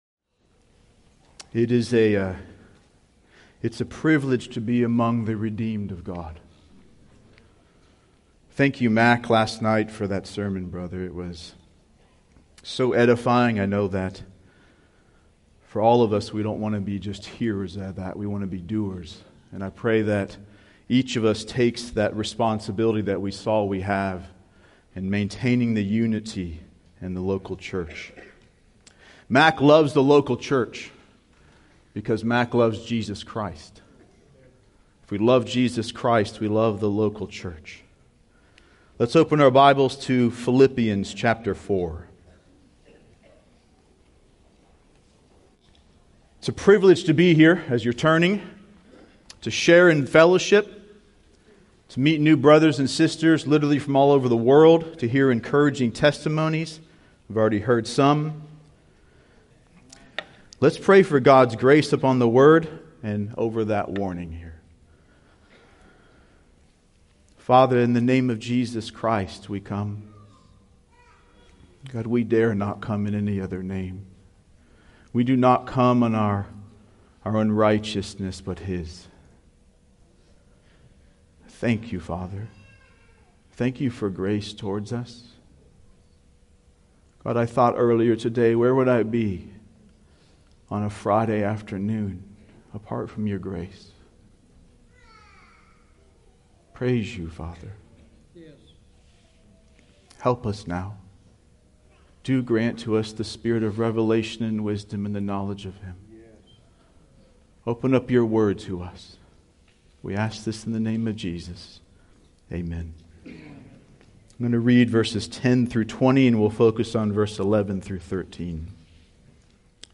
2017 Fellowship Conference